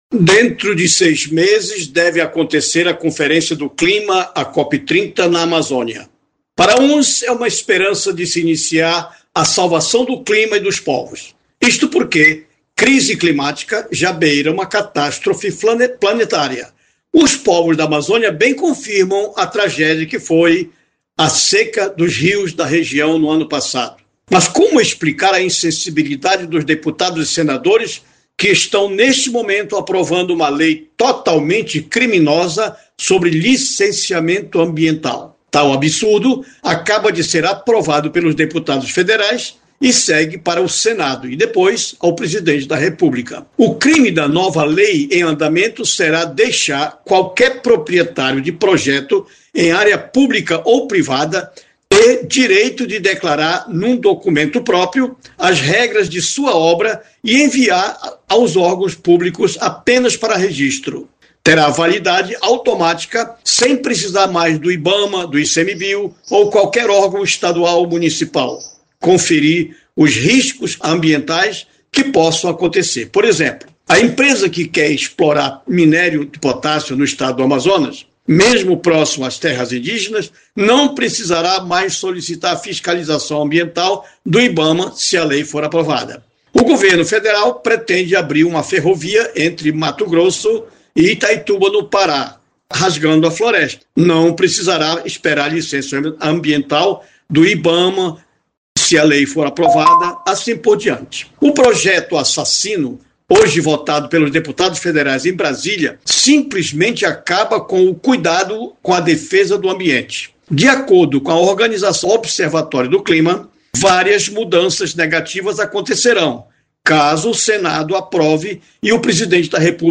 Confira o editorial